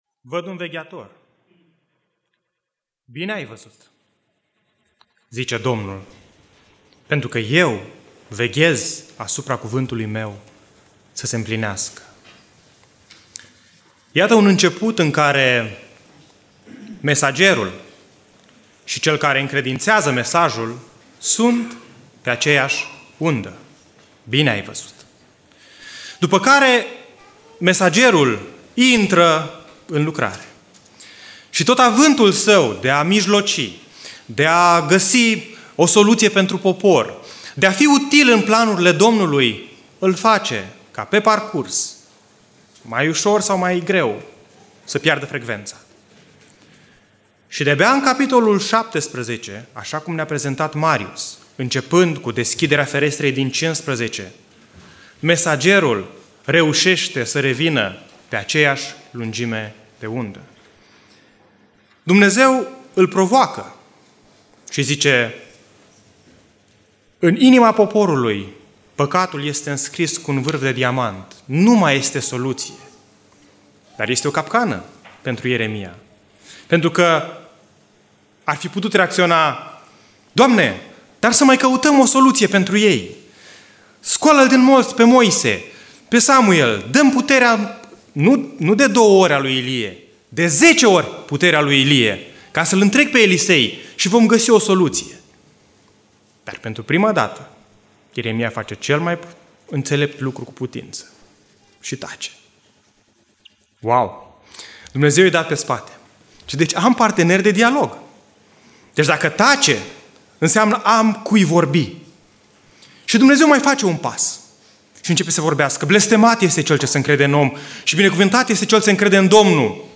Biserica Neemia - Portal materiale - Predica Aplicatie - Ieremia cap. 14-15